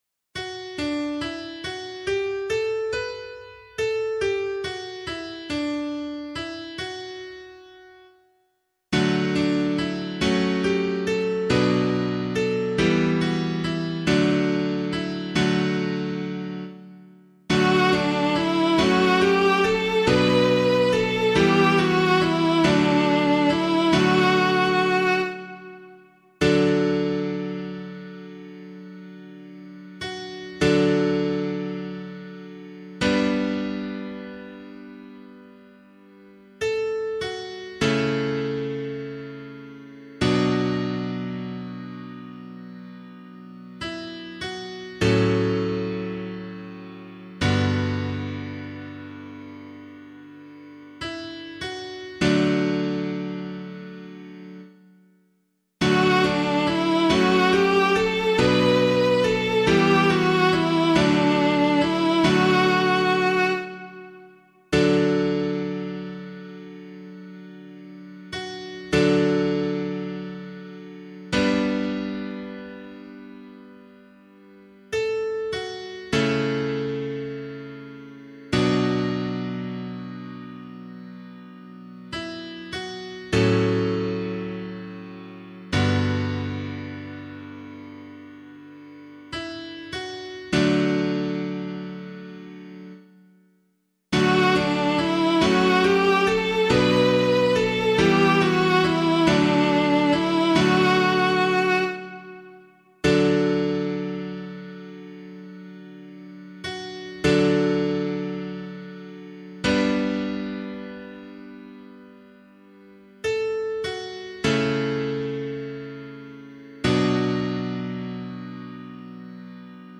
033 Trinity Psalm B [Abbey - LiturgyShare + Meinrad 5] - piano.mp3